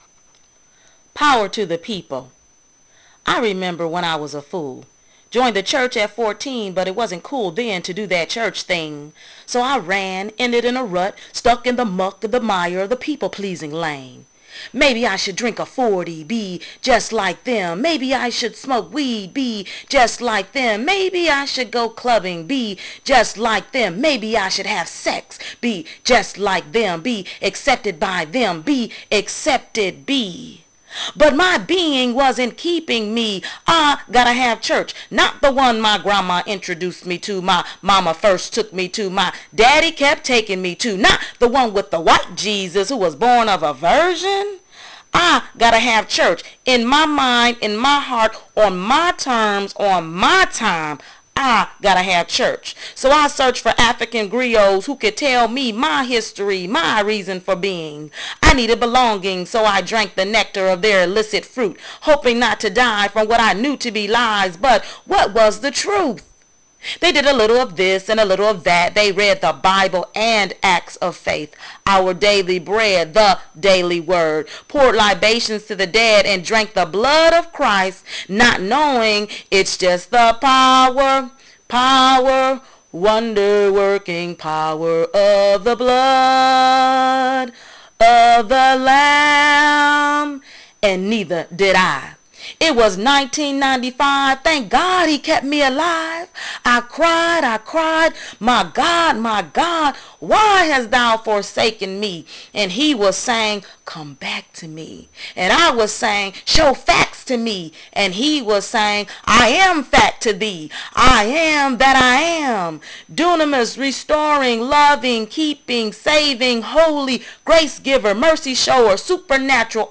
This is the savior, my savior, that I celebrate after rejecting for so many years. Listen to the following poem that tells of my journey.